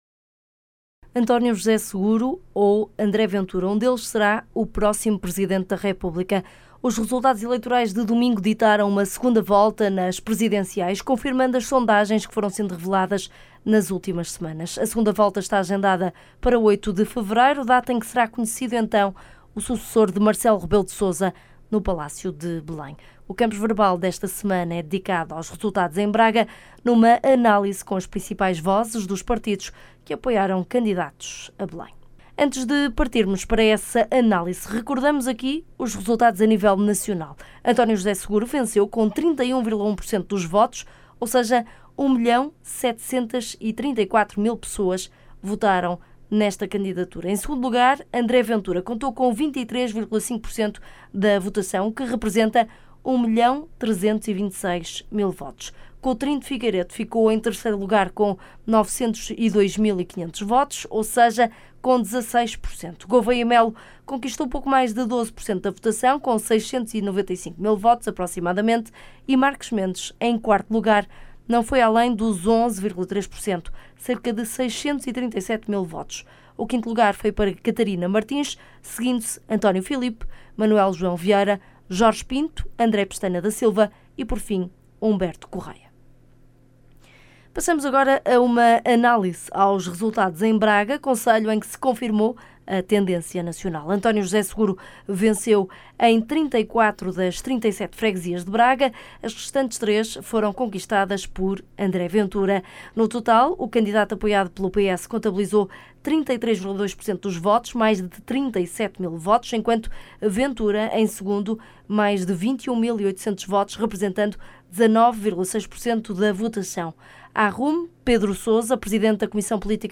No programa de hoje entrevistamos